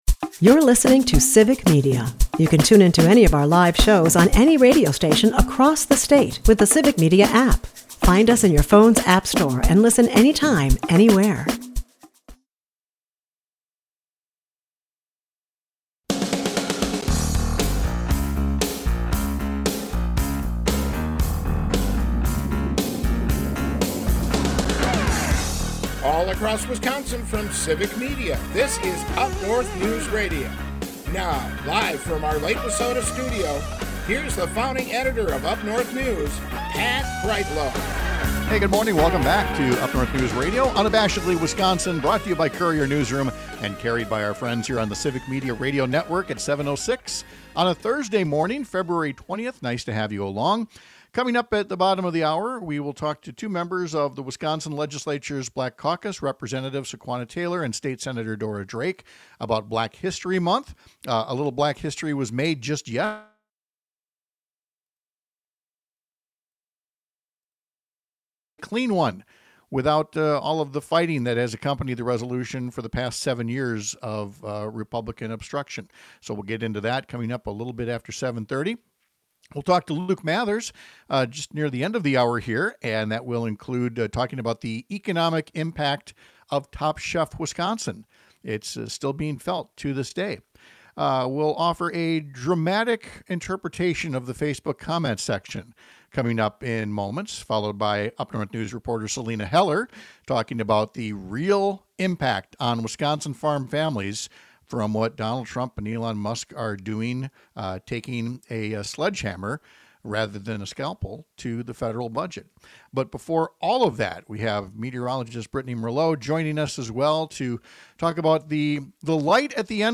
airs on several stations across the Civic Media radio network, Monday through Friday from 6-8 am.